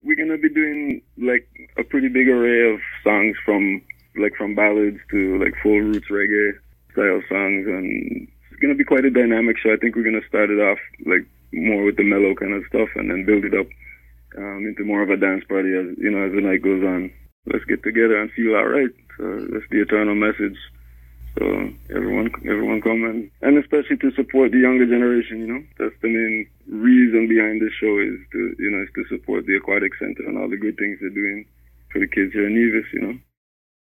Reggae musician